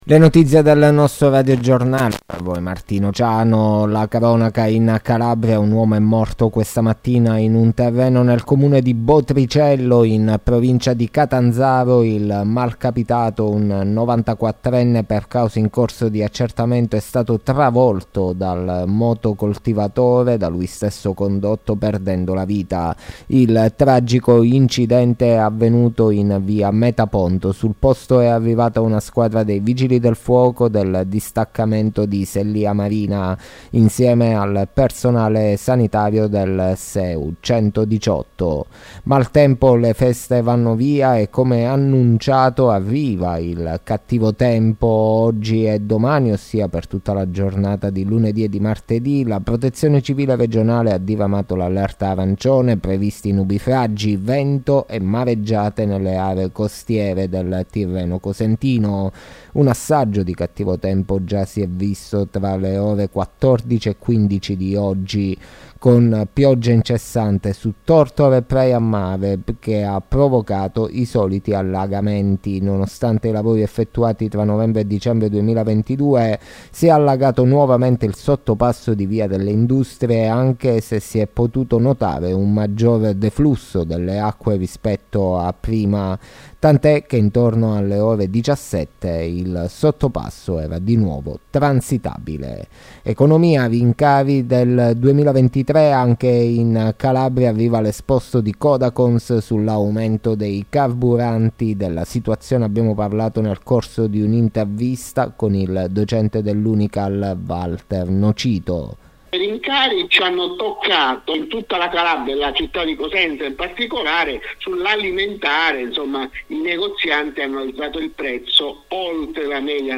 LE NOTIZIE DELLA SERA DI LUNEDì 09 GENNAIO 2023